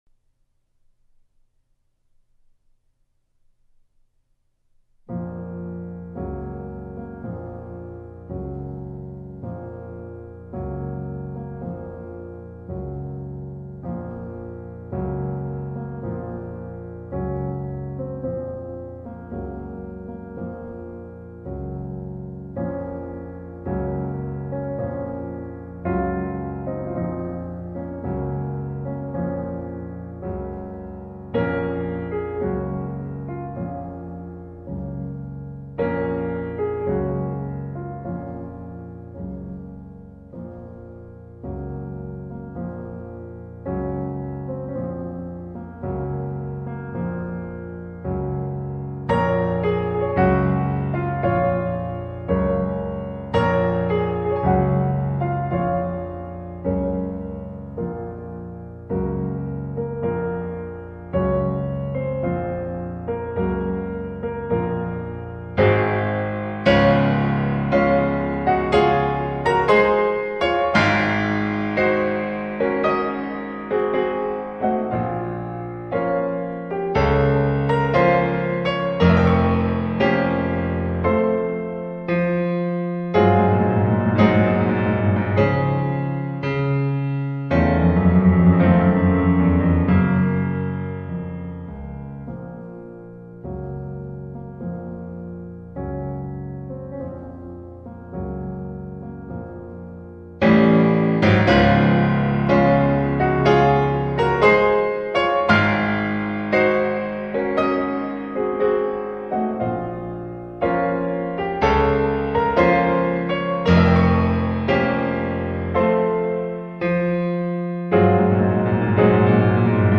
в полном исполнении на пианино